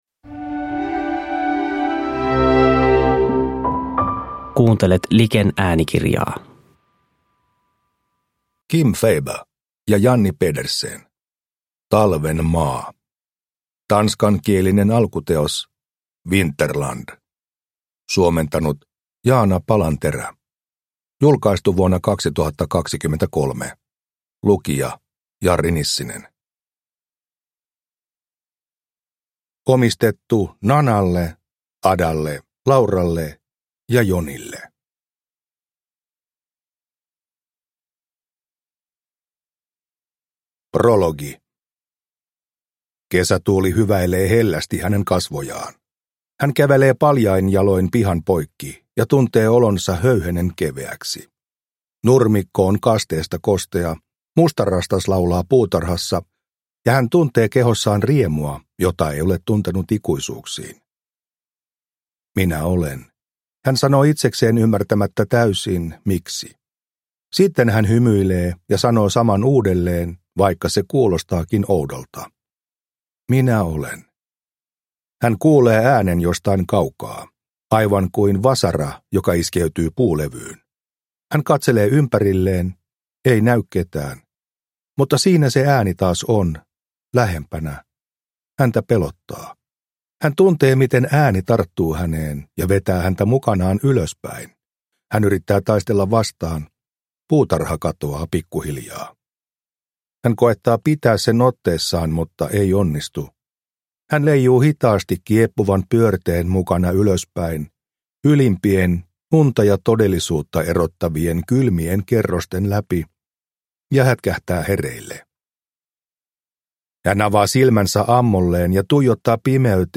Talven maa – Ljudbok – Laddas ner